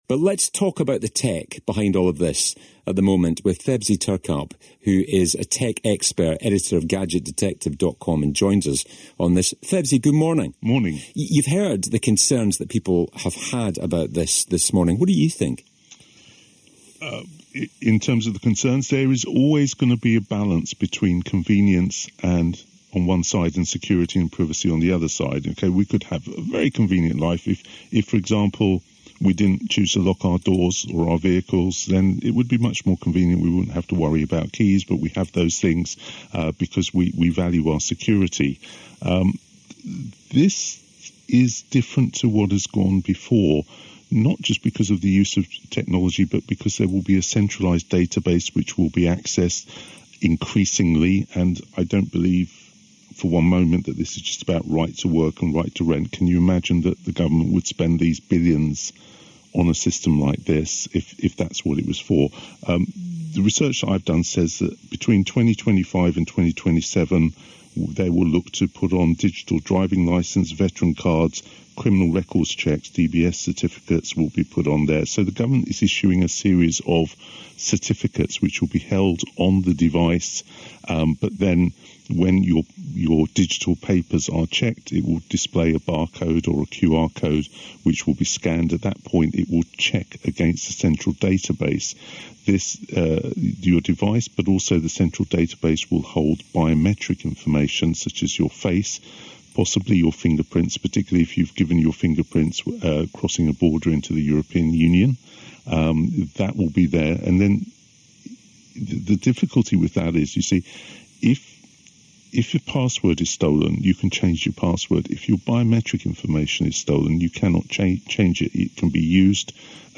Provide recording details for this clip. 26th September 2025 - Discussing ID Cards on BBC Radio Scotland